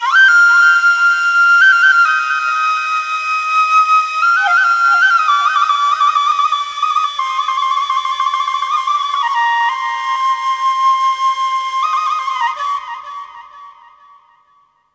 EASTFLUTE3-L.wav